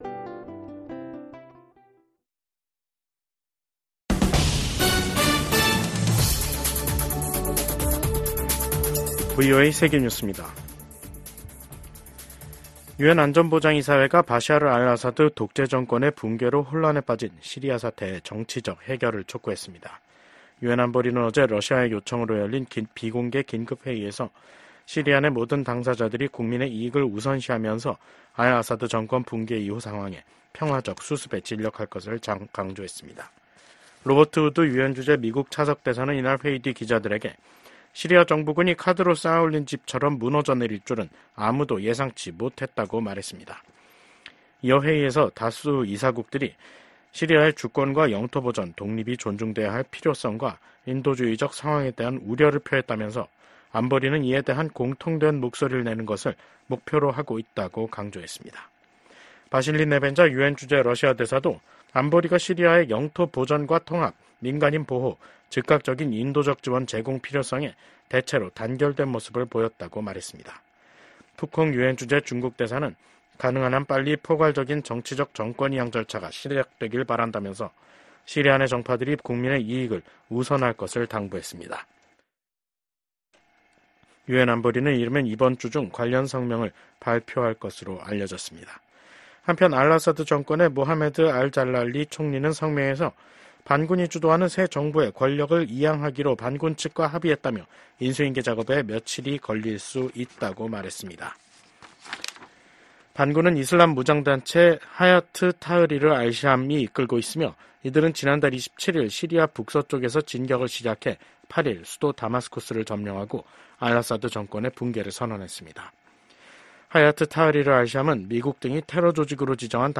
VOA 한국어 간판 뉴스 프로그램 '뉴스 투데이', 2024년 12월 10일 2부 방송입니다. 비상계엄 사태를 수사하는 한국 검찰은 이 사태를 주도한 혐의를 받고 있는 김용현 전 국방부 장관에 대해 구속영장을 청구했습니다. 미국 국무부는 한국의 정치적 혼란 상황이 법치에 따라 해결돼야 한다는 원칙을 재확인했습니다.